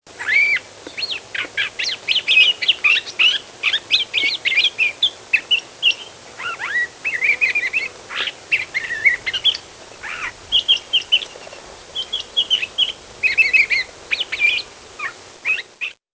northern mockingbird
This is a Mockingbird's "whisper song", sung in mid October. Mockers and other songbirds sometimes sing these "whisper songs", which can only be heard a short distance away (50 feet or less) in the latter parts of the year. This recording is from only 10 feet with a very sensitive shotgun mic.